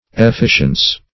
efficience - definition of efficience - synonyms, pronunciation, spelling from Free Dictionary
efficience \ef*fi"cience\ ([e^]f*f[i^]sh"ens), efficiency